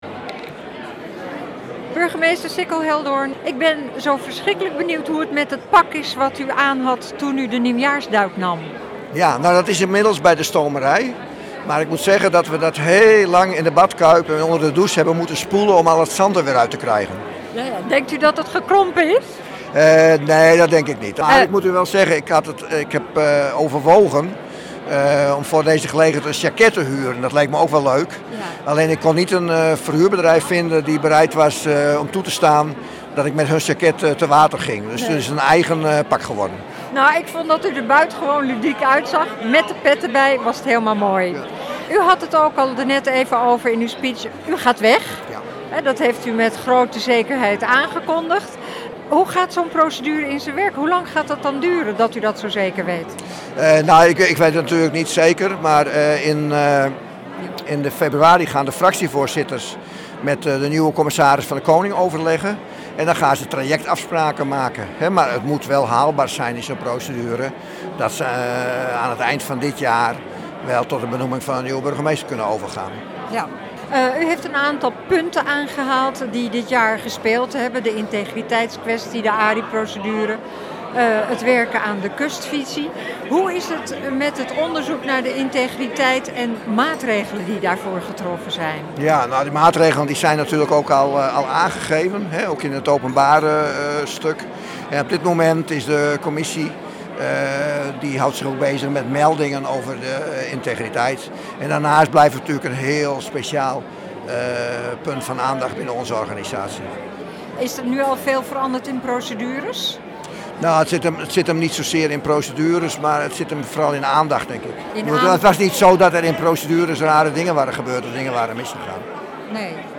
Impressie van de nieuwjaarsreceptie in Huizen met een gesprek met Burgemeester Sicko Heldoorn